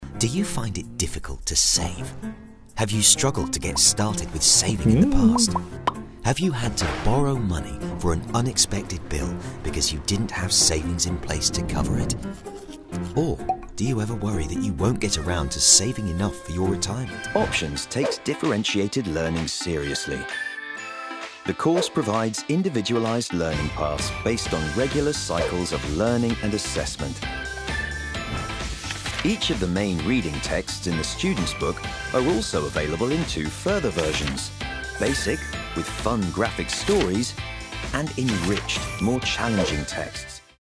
Engels (Brits)
Commercieel, Diep, Veelzijdig, Warm, Zakelijk
Explainer